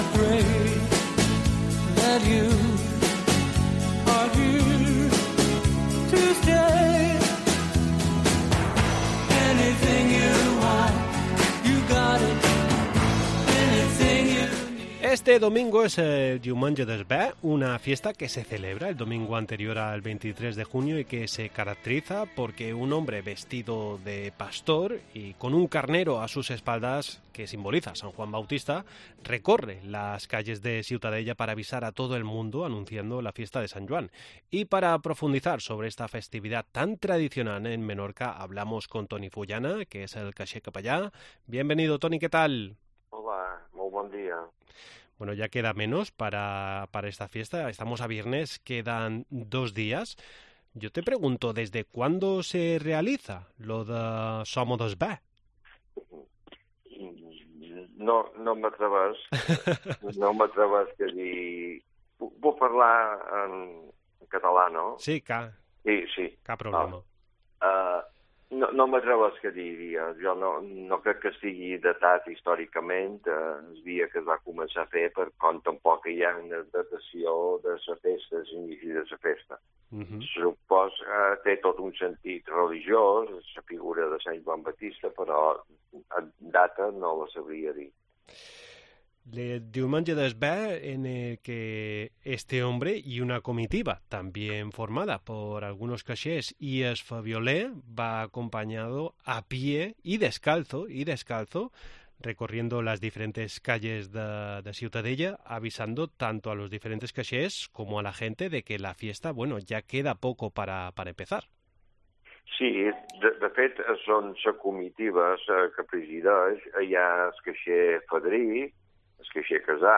Este domingo es el Diumengue d´es Be, una fiesta que se celebra el domingo anterior al 23 de junio y que se caracteriza porque un hombre vestido de pastor y con una oveja a sus espaldas recorre las calles de Ciutadella para avisar a todo el mundo anunciando la fiesta de San Joan. Para profundizar sobre esta festividad tan tradicional en Menorca hablamos con